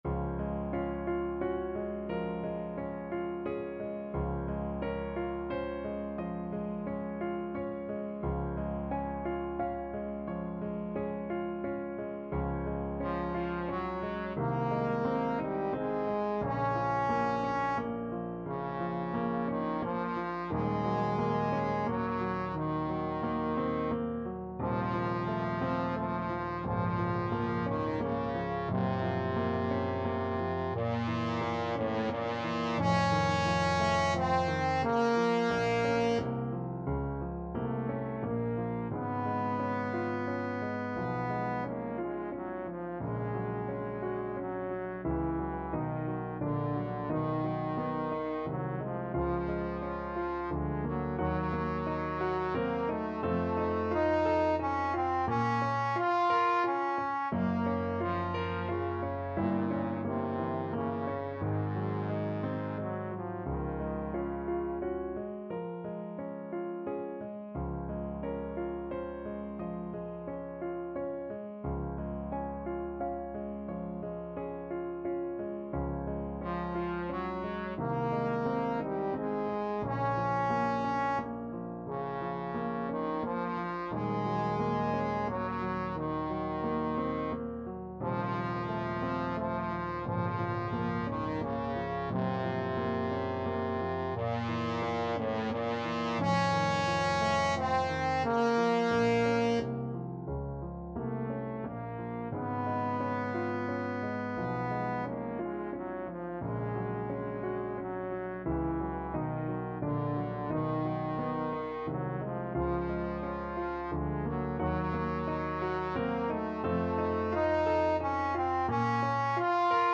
Trombone
Db major (Sounding Pitch) (View more Db major Music for Trombone )
~ = 88 Andante
6/4 (View more 6/4 Music)
Ab3-F5
Classical (View more Classical Trombone Music)